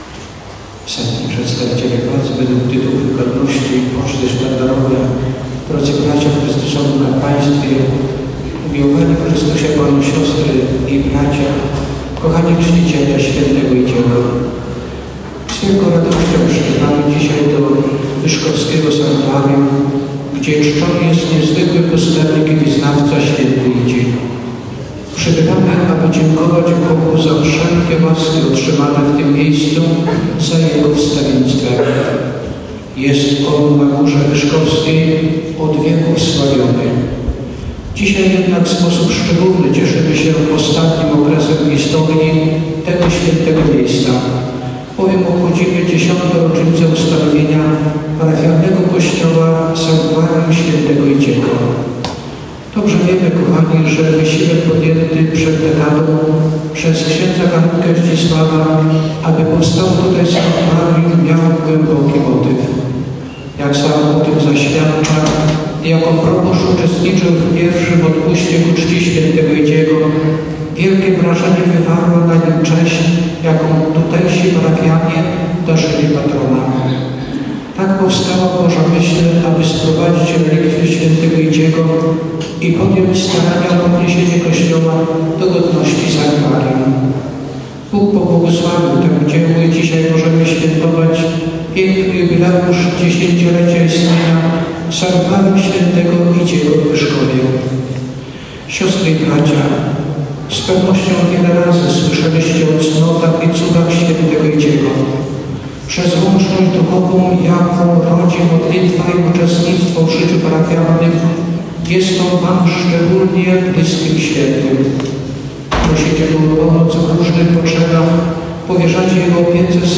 W niedzielę 1 września Sanktuarium pw. św. Idziego w Wyszkowie świętowało odpust parafialny ku czci swojego patrona połączony z jubileuszem 10-lecia wyniesienia kościoła do godności Sanktuarium. Uroczystej sumie o godzinie 12.00 przewodniczył ks. bp Tadeusz Bronakowski.
Cała homilia ks. bp. Tadeusza Bronakowskiego: